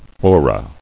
Fora [fo·ra] A forum is a public discussion.